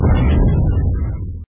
audio_lose.mp3